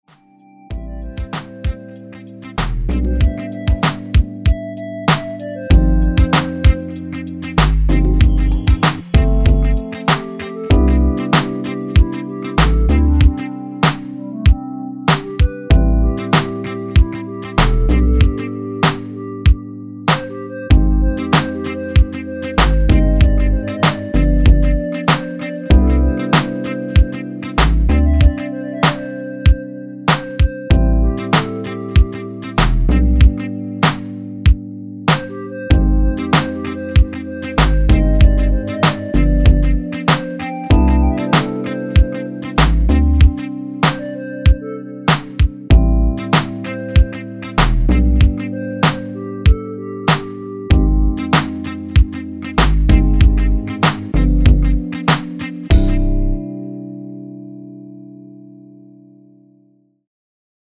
엔딩이 페이드 아웃이라 라이브 하시기 좋게 엔딩을 만들어 놓았습니다.(미리듣기 참조)
원키에서(-2)내린 멜로디 포함된 MR입니다.
앞부분30초, 뒷부분30초씩 편집해서 올려 드리고 있습니다.